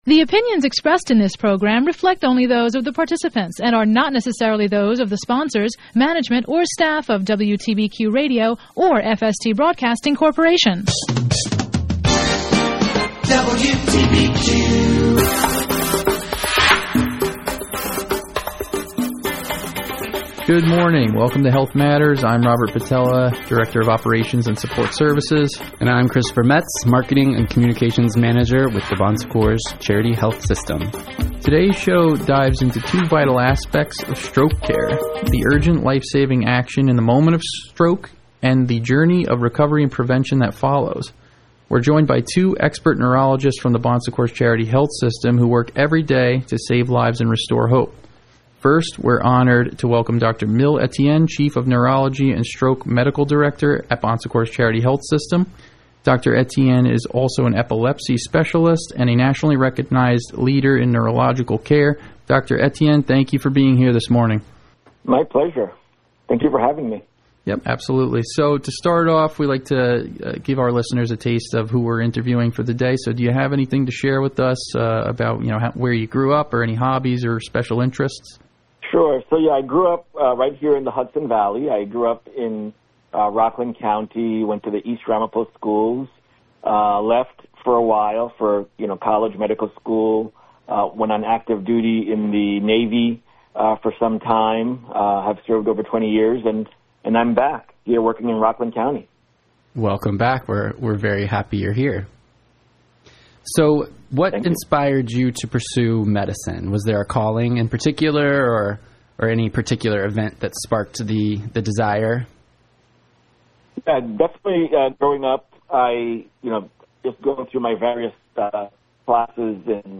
Tune in for a lifesaving conversation with stroke experts from Bon Secours Charity Health System